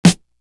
Shootem Up Snare.wav